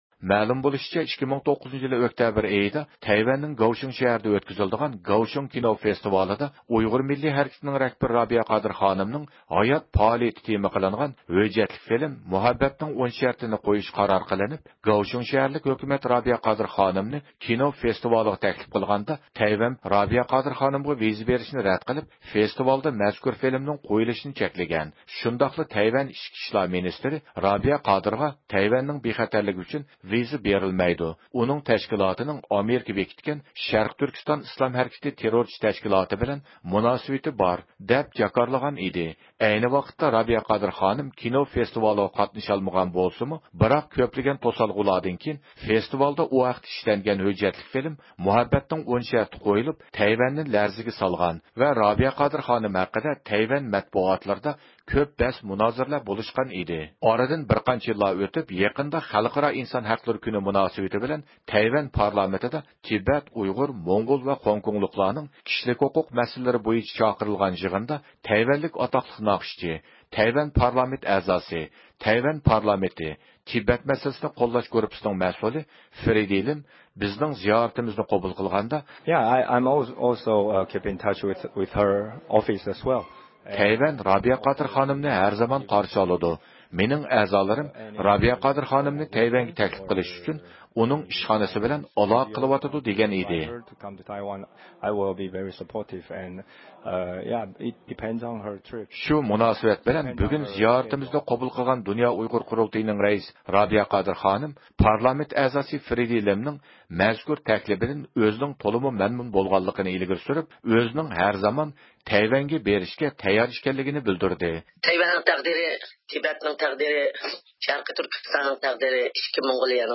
شۇ مۇناسىۋەت بىلەن بۈگۈن زىيارىتىمىزنى قوبۇل قىلغان دۇنيا ئۇيغۇر قۇرۇلتىيىنىڭ رەئىسى رابىيە قادىر خانىم پارلامېنت ئەزاسى فرېدي لىمنىڭ مەزكۇر تەكلىپىدىن ئۆزىنىڭ تولىمۇ مەمنۇن بولغانلىقىنى ئىلگىرى سۈرۈپ، ھەر زامان تەيۋەنگە بېرىشكە تەييار ئىكەنلىكىنى بىلدۈردى.